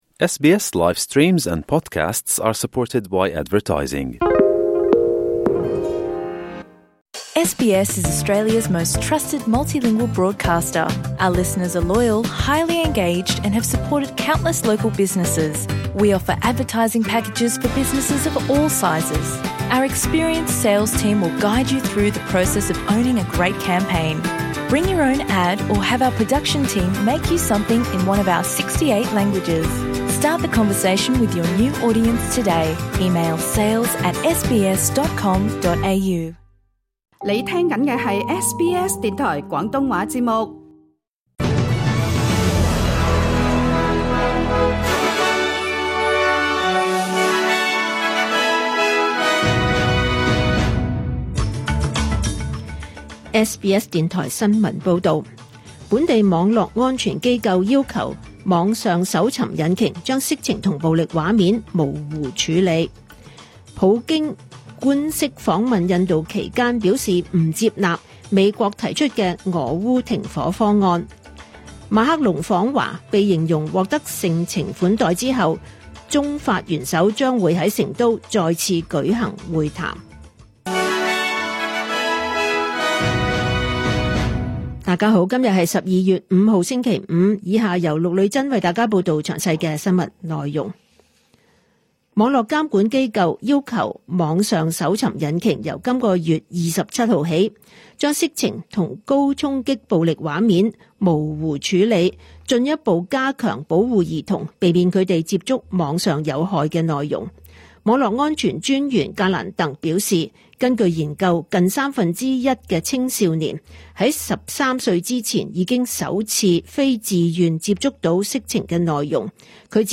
2025 年 12 月 5 日 SBS 廣東話節目詳盡早晨新聞報道。